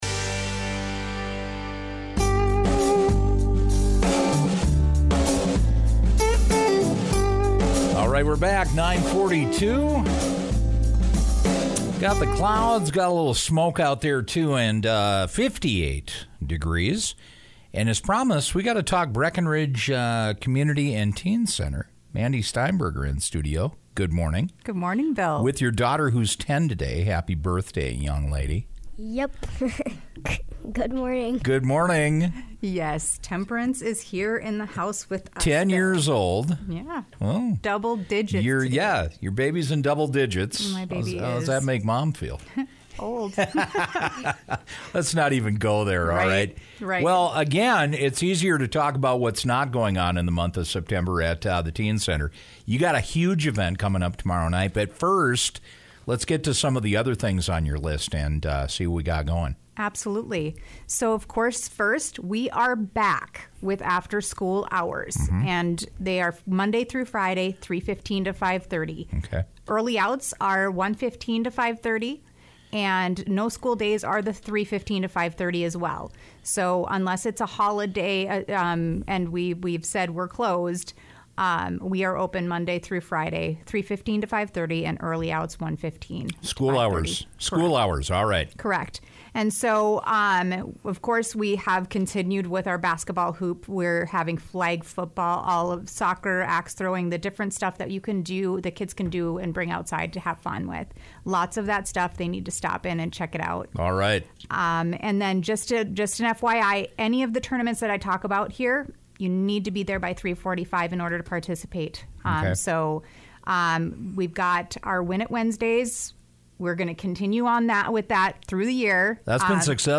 dropped by the KBWM Morning Show on Wednesday to talk about the upcoming event